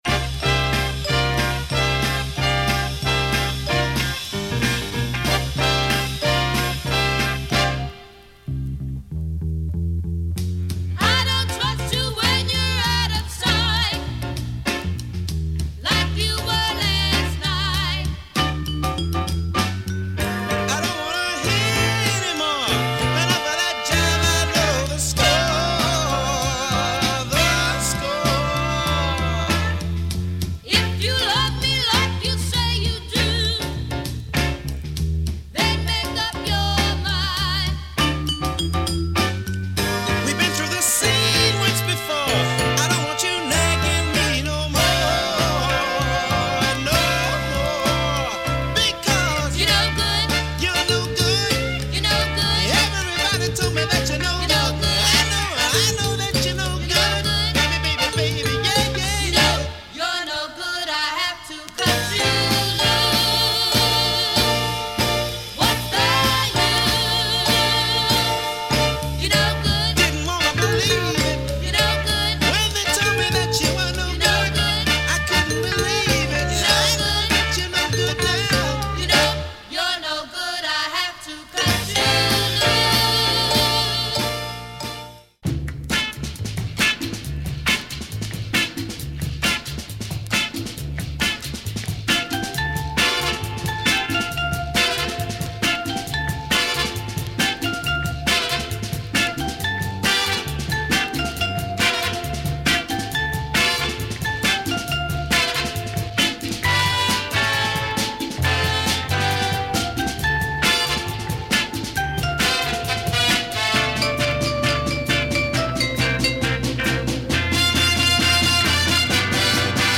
Superb latin soul with breaks